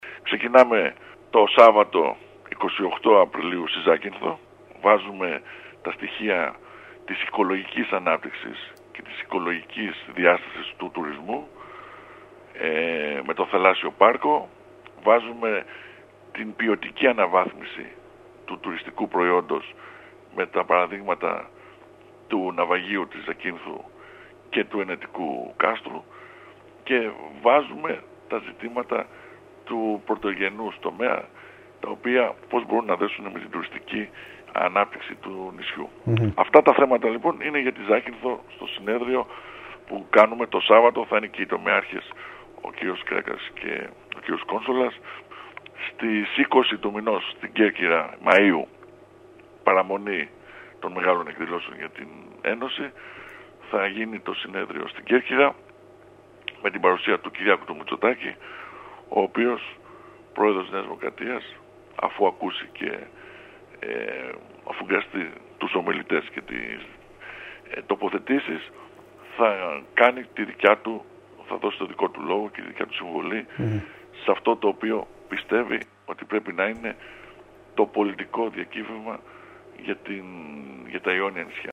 Ακούστε απόσπασμα των δηλώσεων του Β. Οικονόμου στον σύνδεσμο που ακολουθεί: